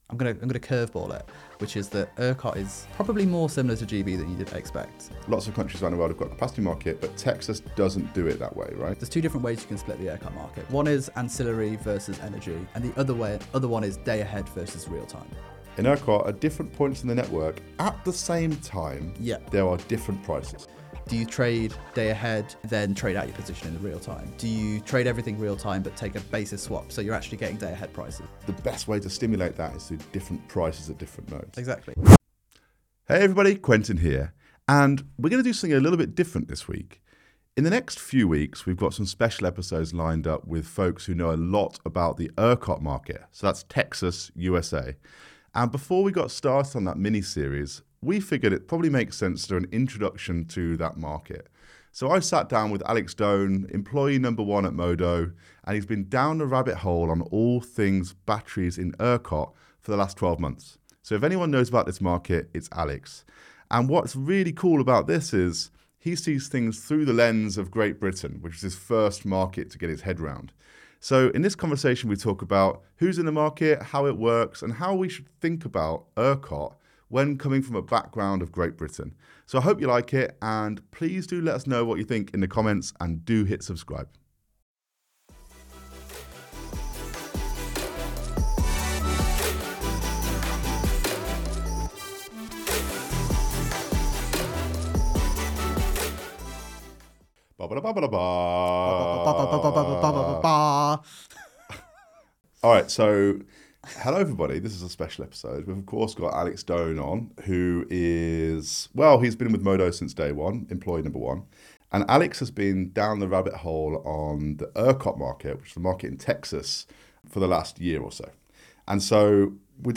Over the course of the conversation, they discuss: